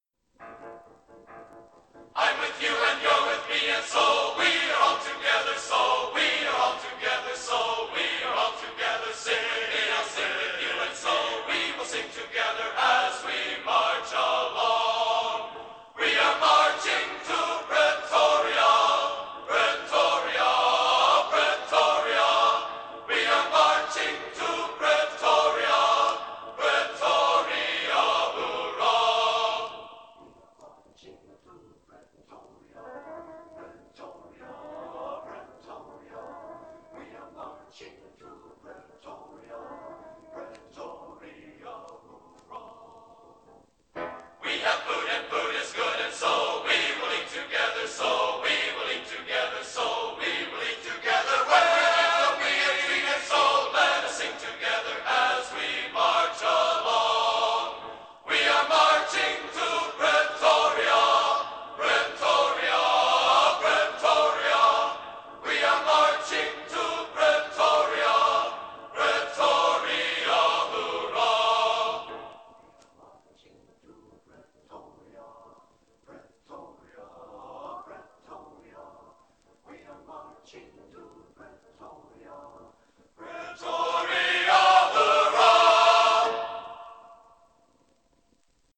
Genre: Traditional | Type: Studio Recording